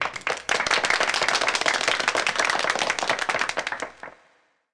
Applause Sound Effect
Download a high-quality applause sound effect.
applause-3.mp3